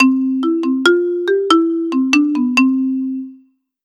Seks ulike ringetoner
4-Marimba.wav